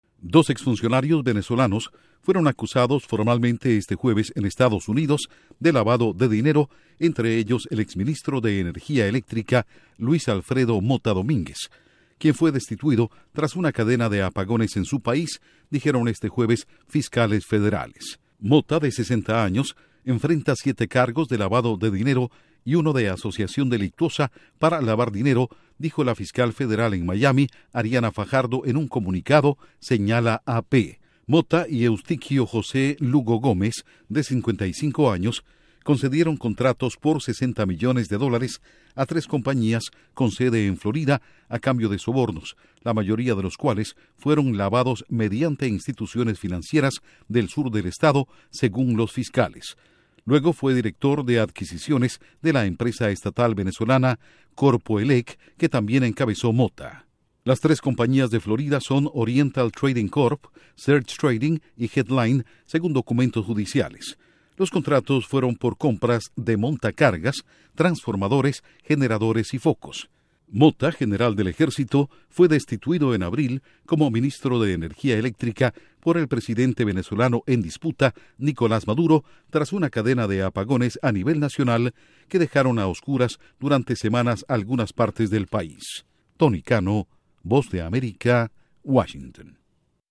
Informa desde la Voz de América en Washington,